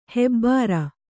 ♪ hebbāra